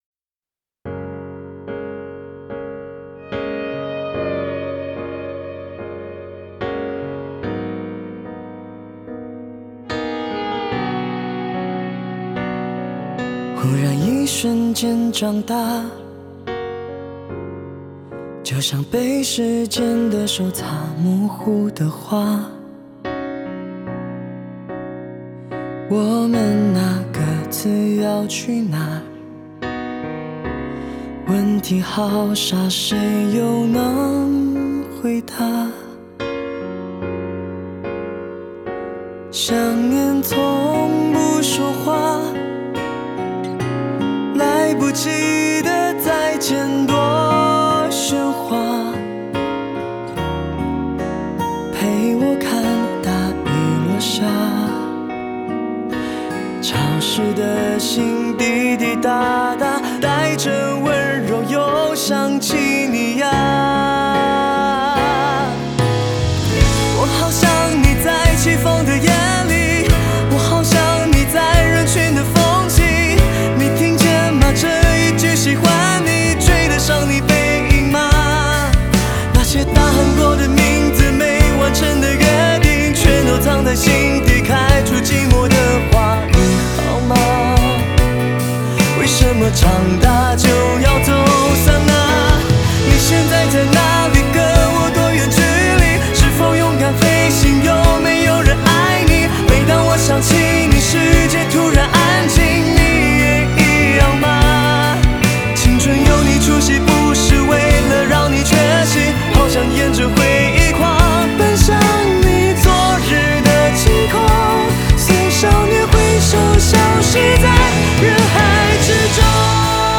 Ps：在线试听为压缩音质节选，体验无损音质请下载完整版
贝斯
和音
吉他